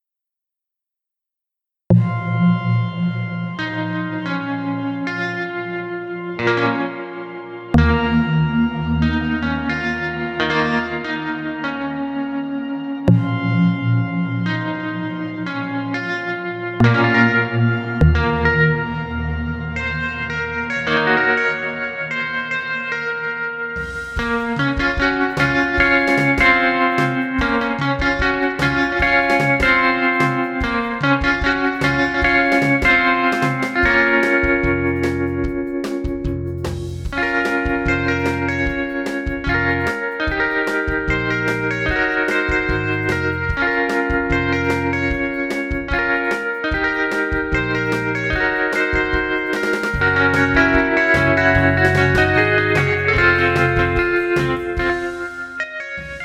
Zwei Amps (wahrscheinlich british und american) sowie ein Federhall bilden die Grundlagen der Klangbildung.
desolate-guitars-1.mp3